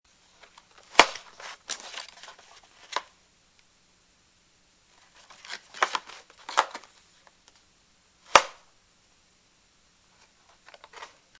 Box Opening